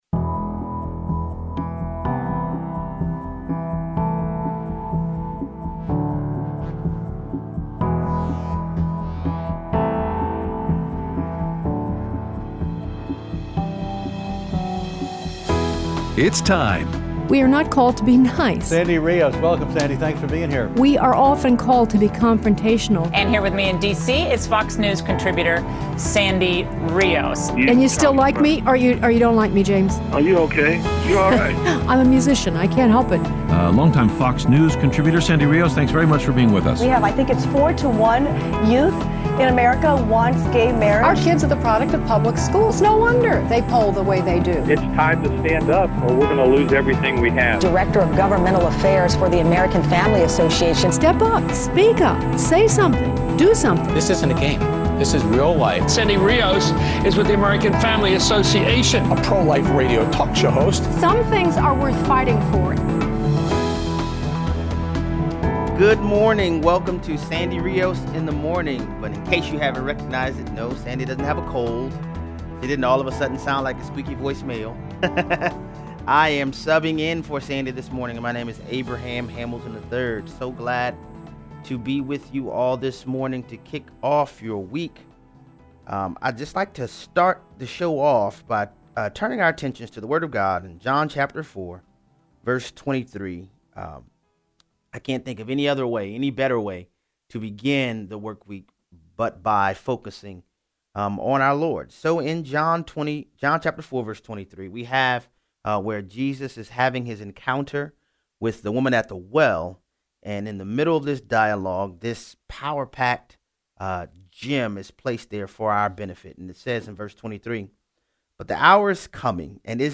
Aired Monday 6/12/17 on AFR 7:05AM - 8:00AM CST